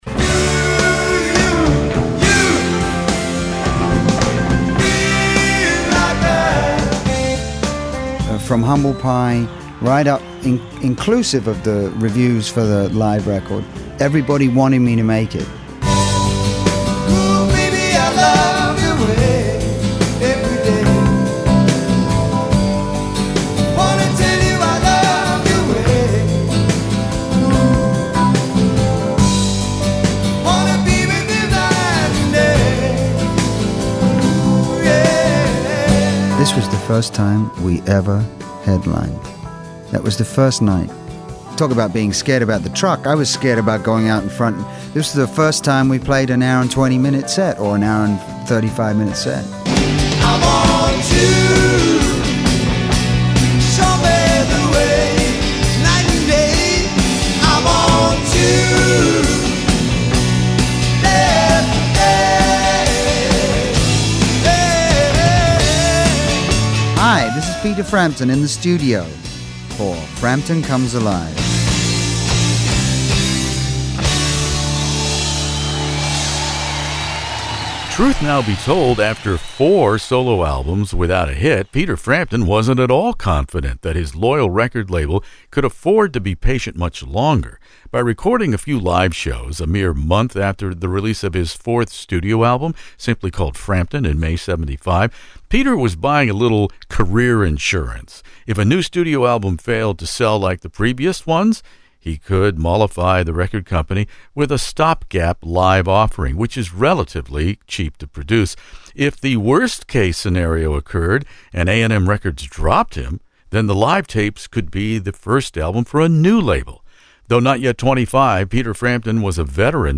Peter Frampton Comes Alive interview